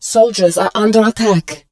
marine_soldierunderattack.wav